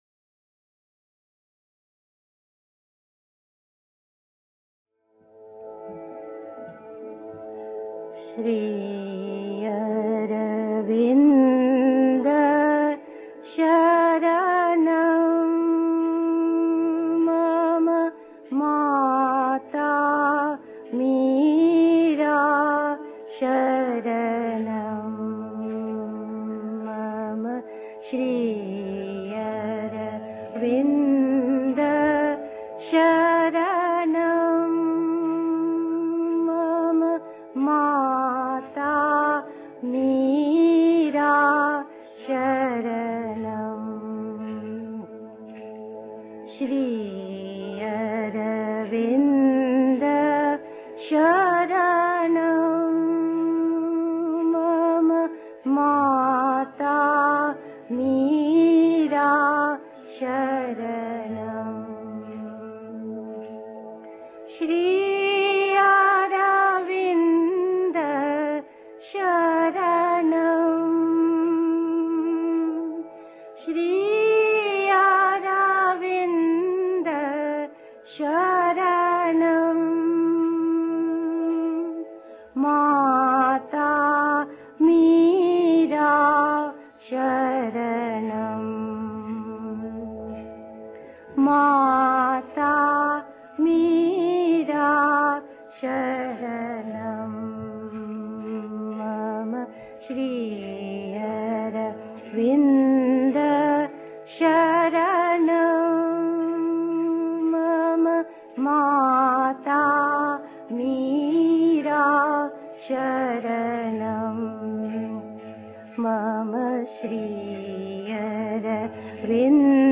1. Einstimmung mit Musik. 2. Die drei Vorgänge des Yoga (Sri Aurobindo, CWSA Vol. 13, pp. 74-76) 3. Zwölf Minuten Stille.